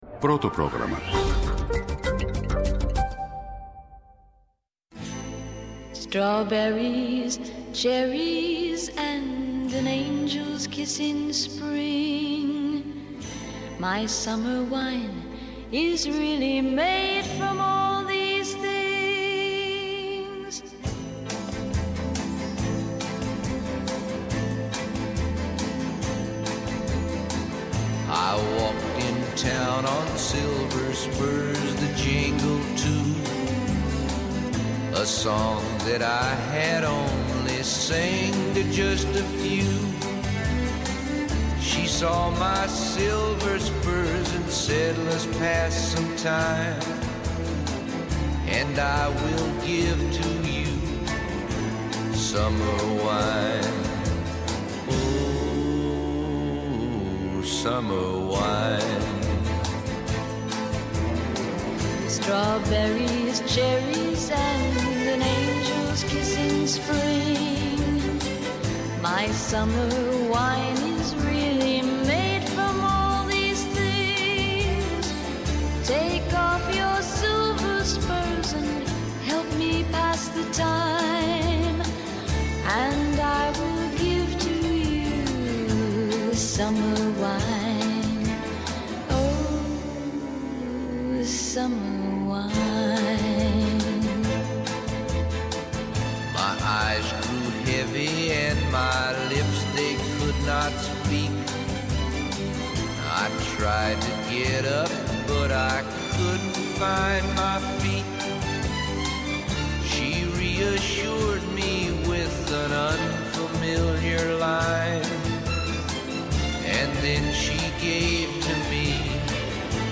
-Ο Ανδρέας Ανδριανόπουλος, πρώην υπουργός, σε μια γεωστρατηγική ανάλυση.
στο Πρώτο Πρόγραμμα της Ελληνικής Ραδιοφωνίας